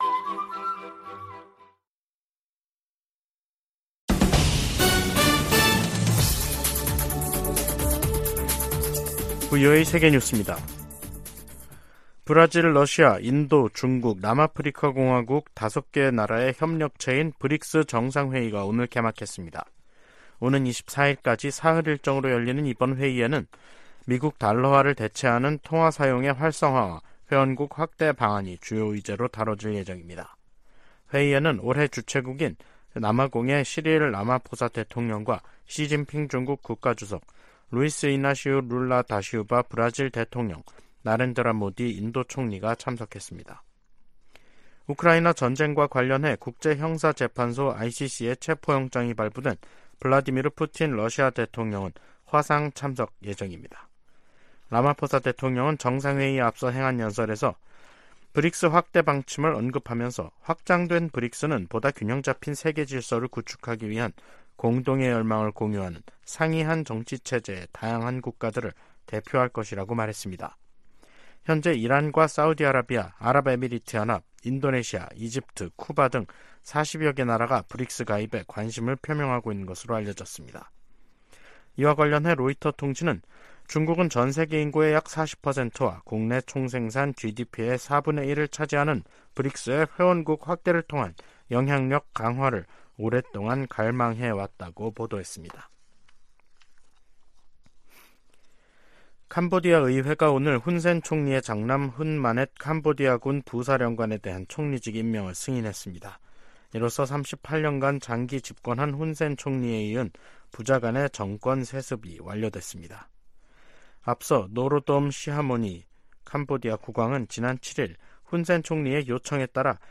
VOA 한국어 간판 뉴스 프로그램 '뉴스 투데이', 2023년 8월 22일 2부 방송입니다. 북한이 실패 3개월만에 군사정찰위성을 다시 발사하겠다고 예고했습니다. 백악관 국가안보회의(NSC) 인도태평양 조정관은 미한일 3국 협력이 위중해진 역내 안보를 지키기 위한 노력의 일환이라고 말했습니다. 6차례에 걸쳐 보내드리는 기획특집 [미한일 정상회의 결산] 첫 시간에 한층 격상된 3국 안보 협력 부분을 살펴봅니다.